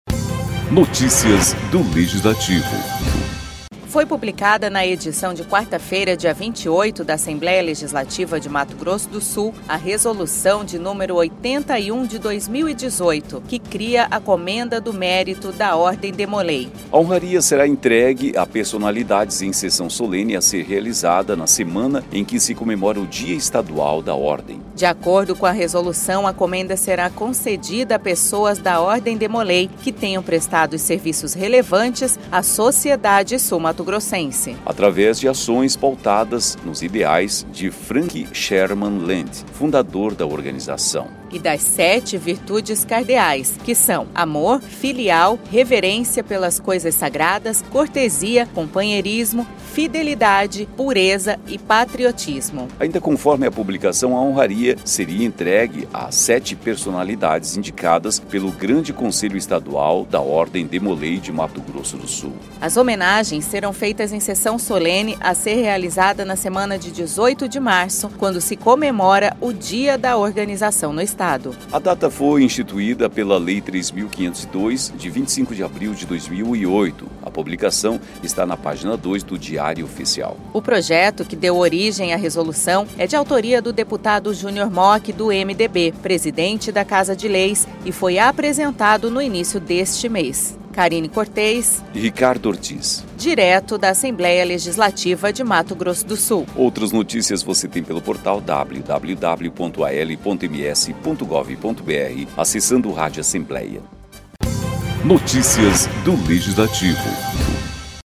Locução: